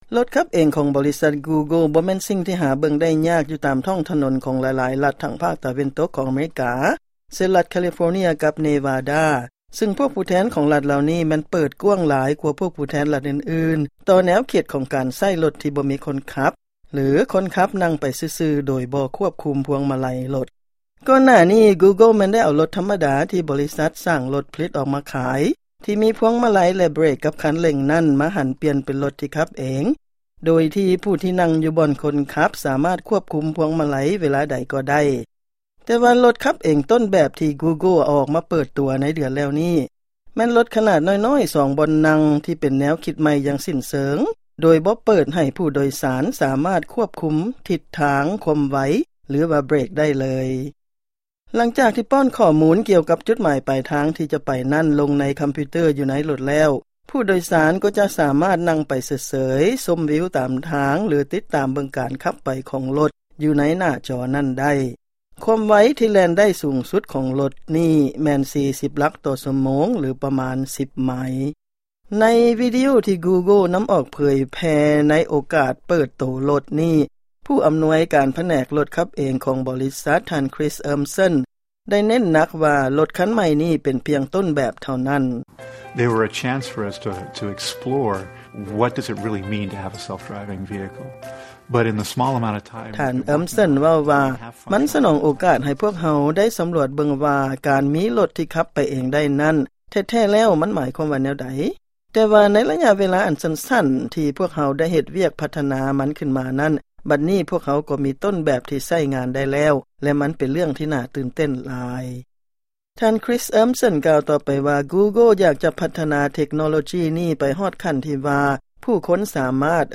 ຟັງລາຍງານກ່ຽວກັບ ລົດຂັບເອງຕົ້ນແບບ ຂອງບໍລິສັດ Google